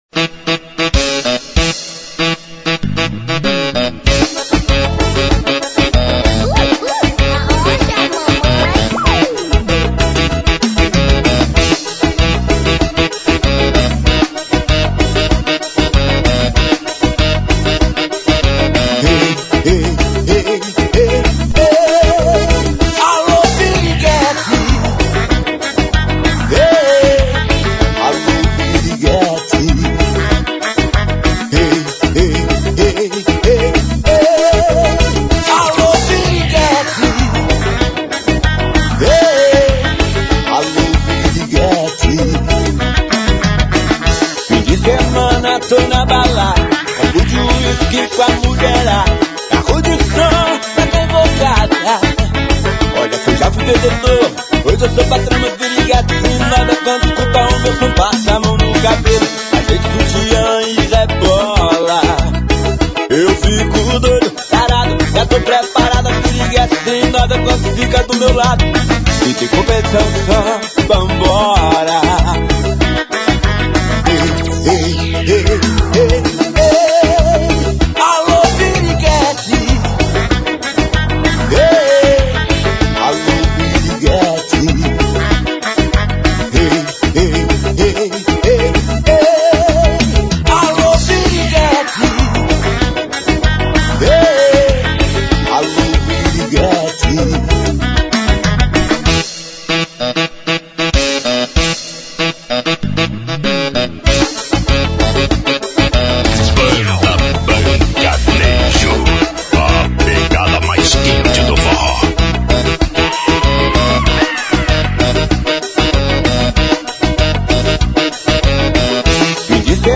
Brega e Forro